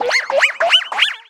Cri d'Otarlette dans Pokémon Soleil et Lune.